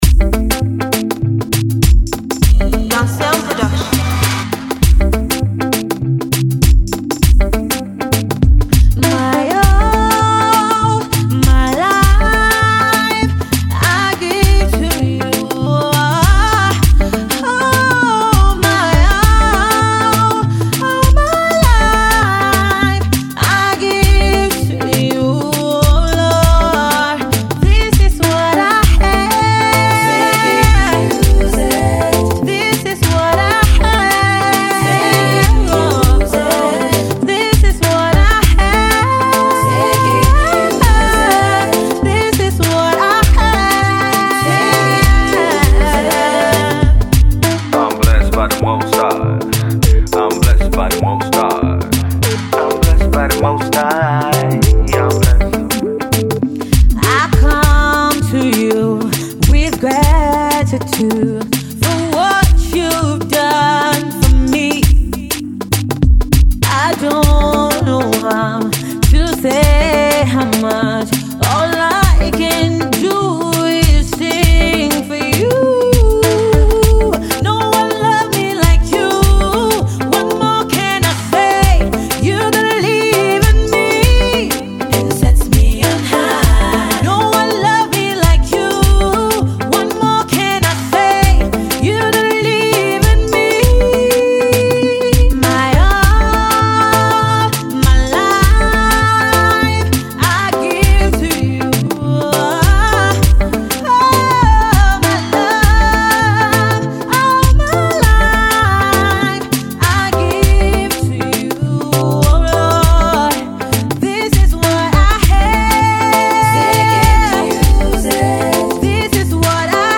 will get you on your feet dancing